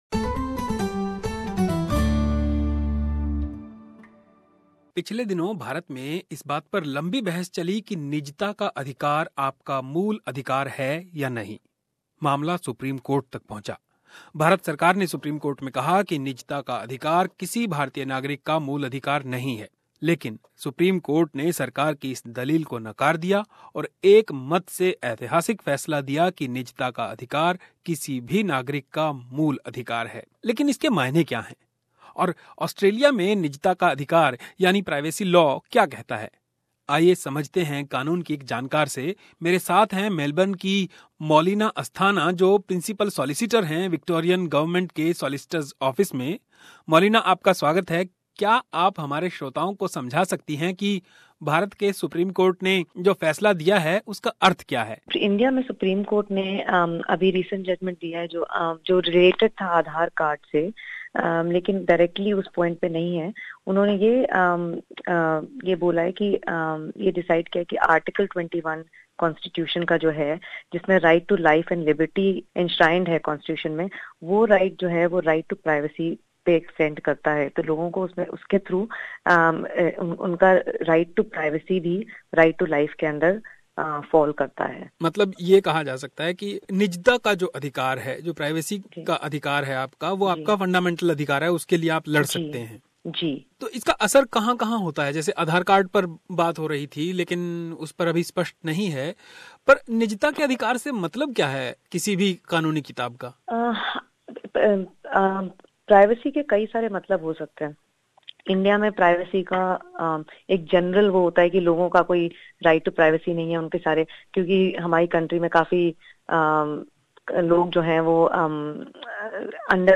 पूरा इंटरव्यू सुनिए ताकि आपको पता चले कि ऑस्ट्रेलिया में कानून आपकी निजता को कैसे देखता है और उसके लिए आप क्या कर सकते हैं.